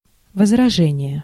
Ääntäminen
IPA : /əb.ˈdʒɛk.ʃən/ US : IPA : [əb.ˈdʒɛk.ʃən]